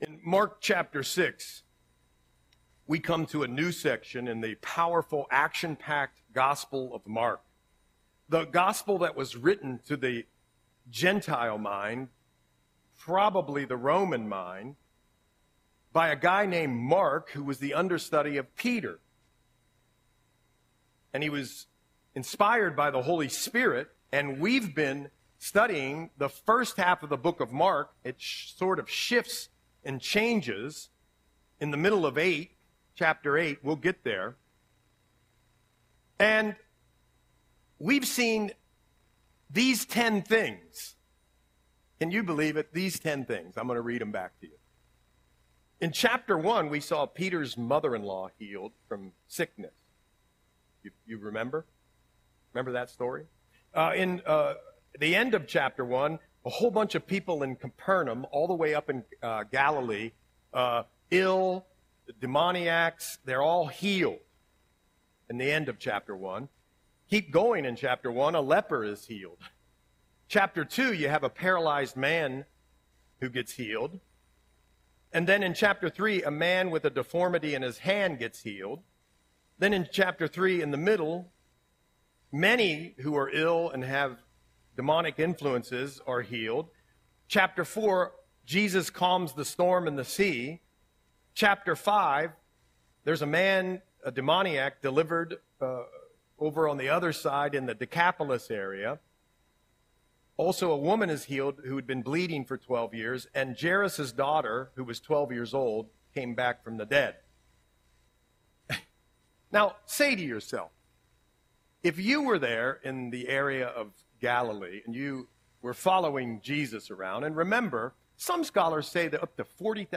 Audio Sermon - December 22, 2024